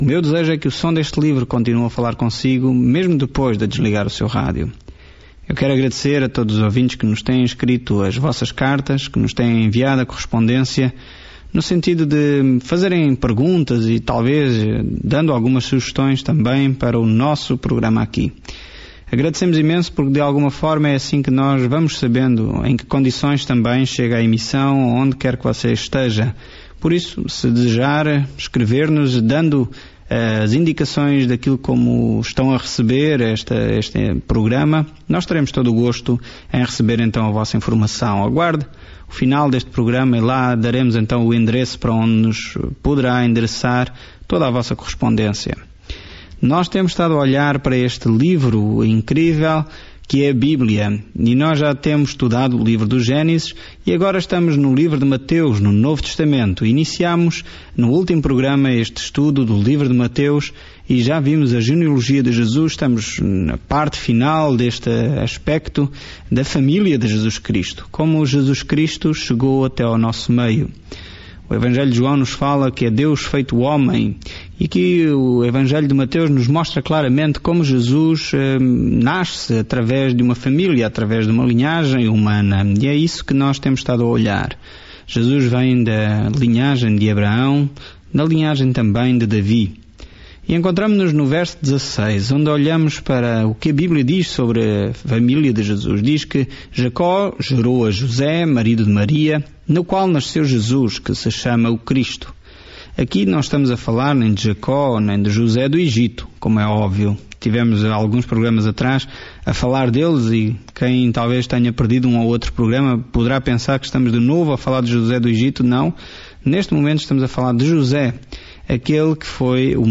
Escritura MATEUS 1:16-25 Dia 2 Iniciar este Plano Dia 4 Sobre este plano Mateus prova aos leitores judeus as boas novas de que Jesus é o Messias, mostrando como Sua vida e ministério cumpriram a profecia do Antigo Testamento. Viaje diariamente por Mateus enquanto ouve o estudo em áudio e lê versículos selecionados da palavra de Deus.